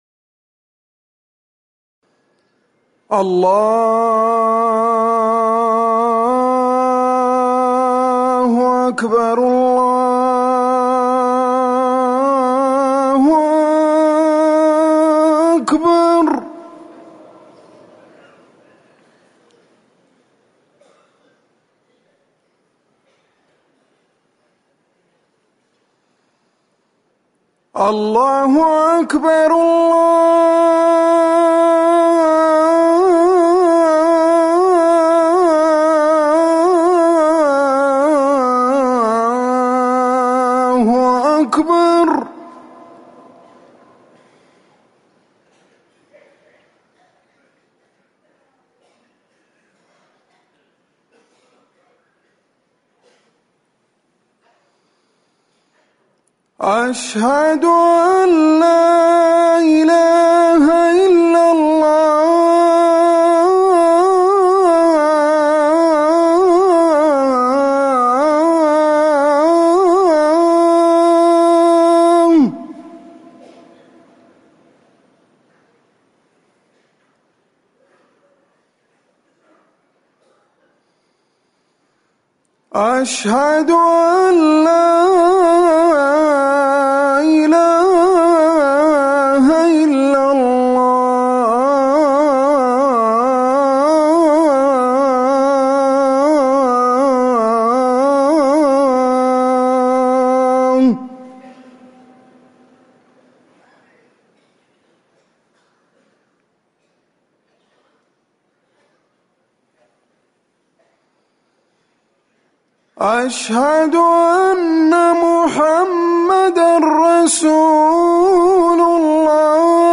أذان الفجر الثاني
المكان: المسجد النبوي